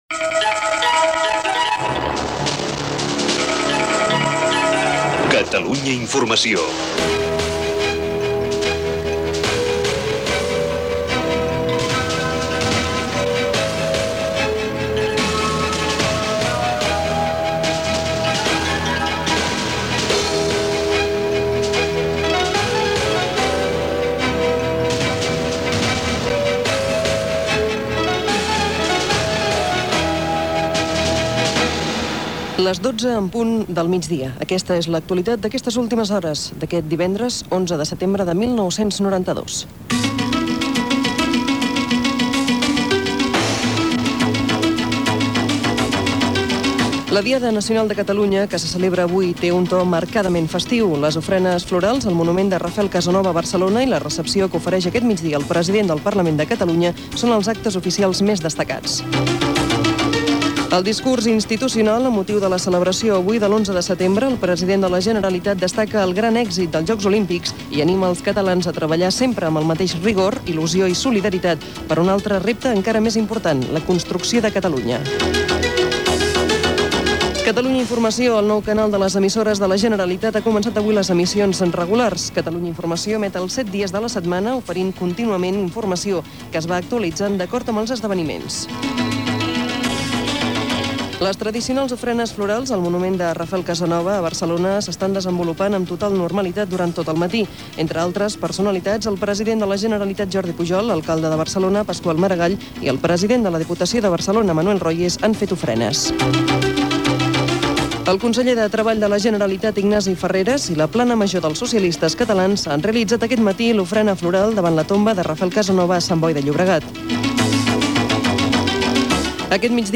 Primer dia d'emissió.
Informatiu